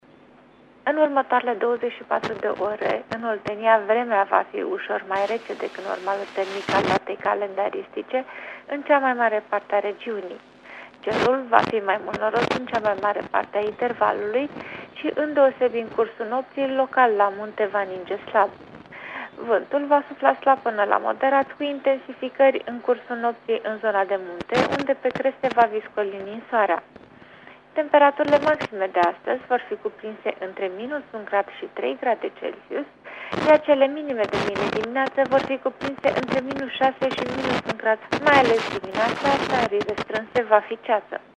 Prognoza meteo 1 ianuarie (audio)